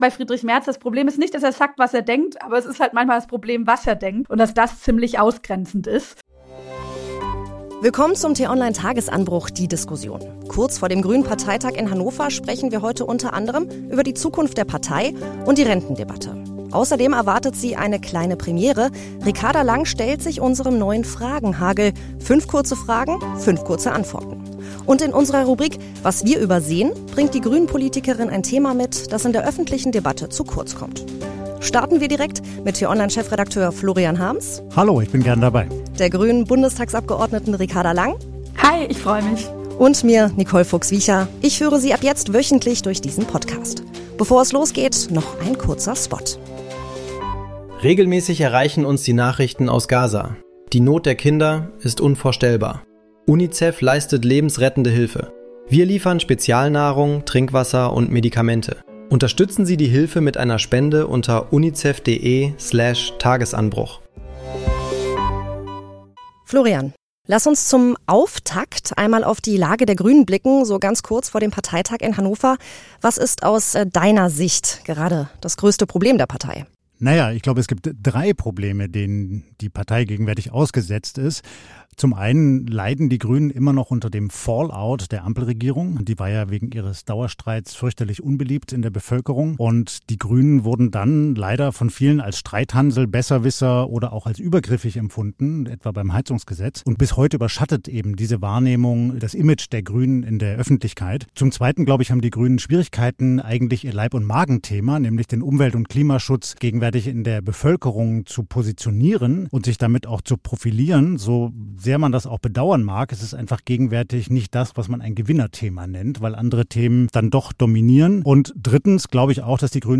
Den „Tagesanbruch“-Podcast gibt es immer montags bis samstags gegen 6 Uhr zum Start in den Tag – am Wochenende mit einer längeren Diskussion.